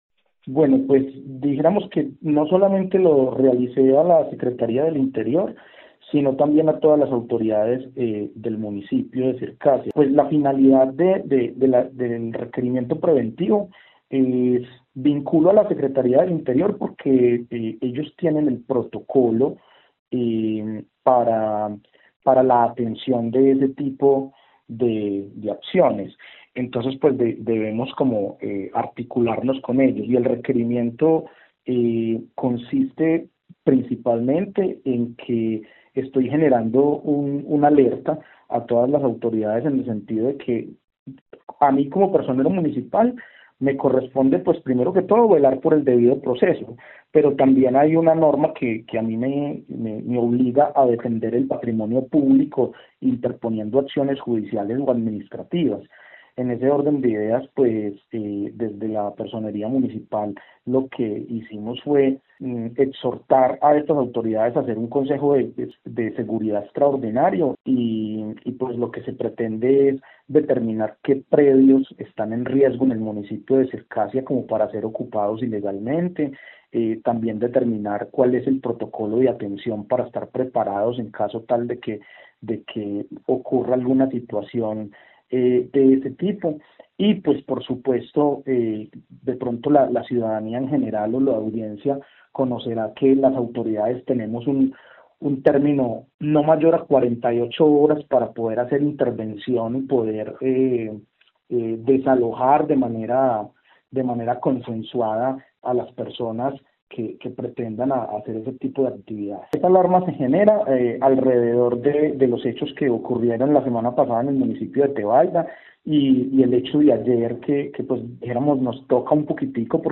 Personero de Circasia